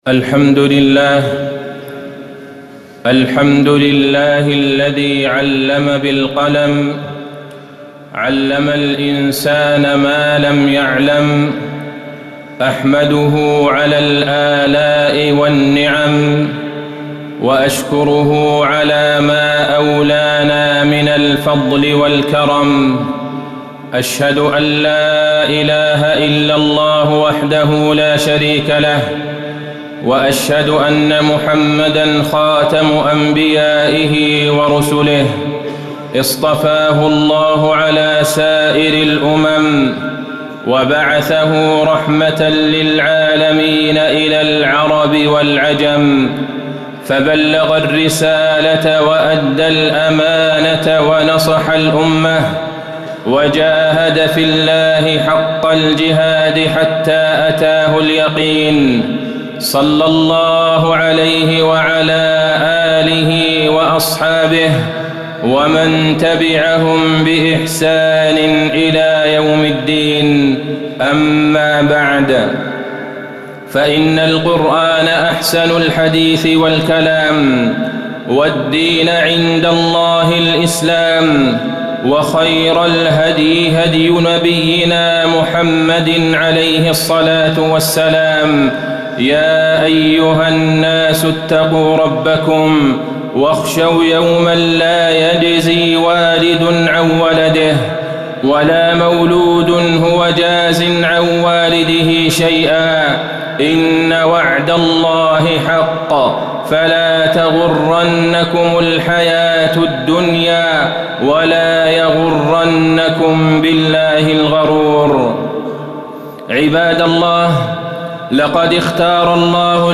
خطبة مكانة العلم والمعلم في الإسلام وفيها: استخلاف الإنسان في الأرض، وتفضيله على باقي الخلق، أول ما نزل به القرآن حث على العلم، ما يحتاجه طالب العلم
تاريخ النشر ٩ جمادى الأولى ١٤٣٩ المكان: المسجد النبوي الشيخ: فضيلة الشيخ د. عبدالله بن عبدالرحمن البعيجان فضيلة الشيخ د. عبدالله بن عبدالرحمن البعيجان مكانة العلم والمعلم في الإسلام